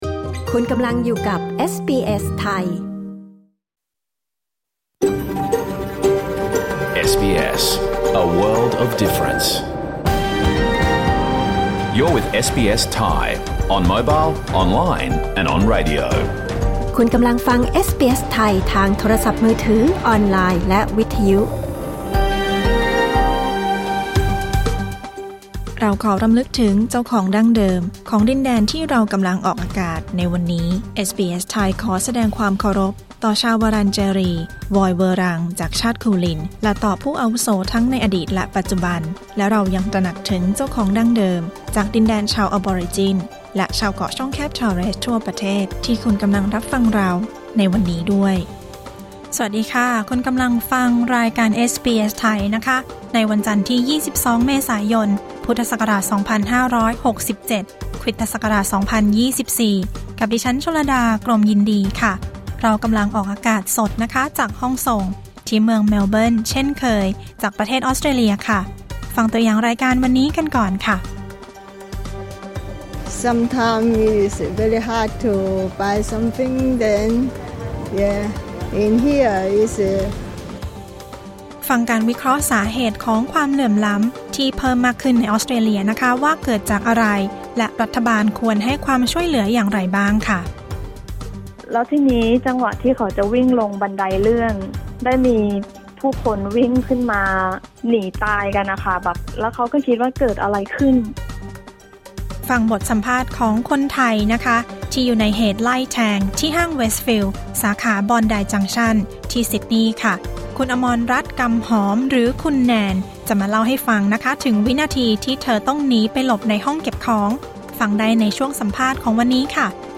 รายการสด 22 เมษายน 2567